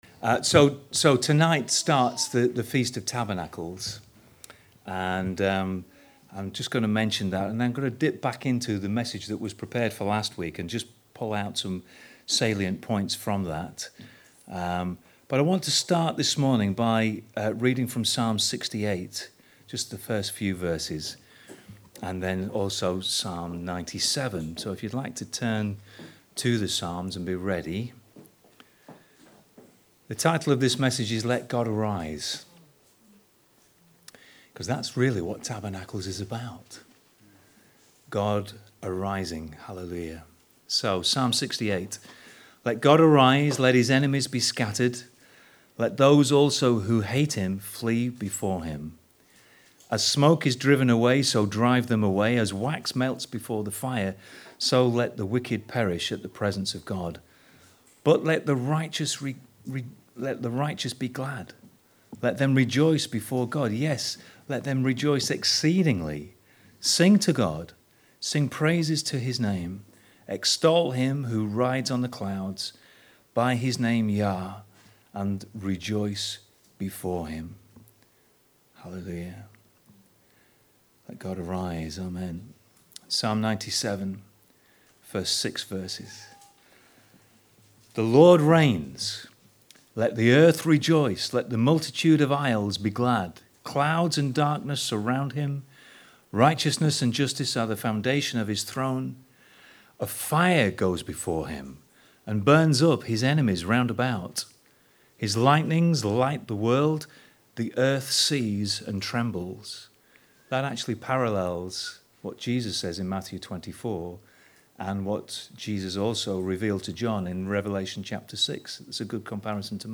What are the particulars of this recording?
(Second ) Advent Sunday